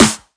• Sharp Acoustic Snare Sample A Key 70.wav
Royality free acoustic snare sound tuned to the A note. Loudest frequency: 3249Hz
sharp-acoustic-snare-sample-a-key-70-DEG.wav